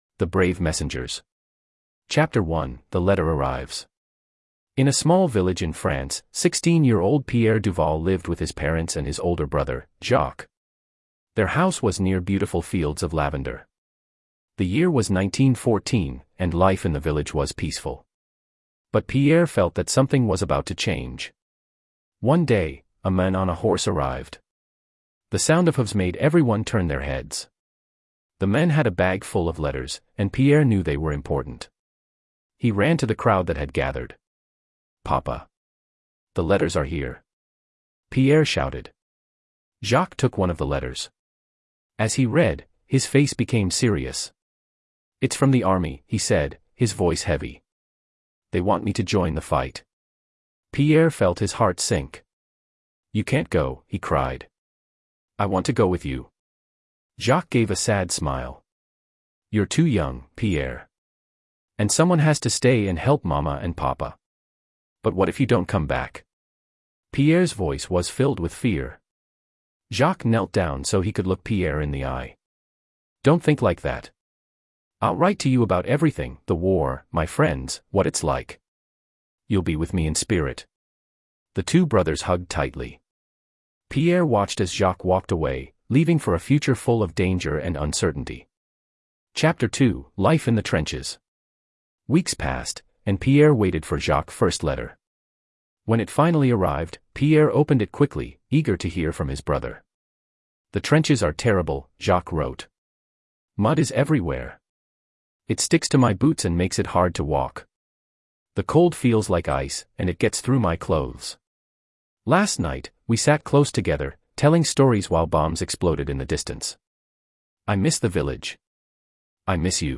More Audio Books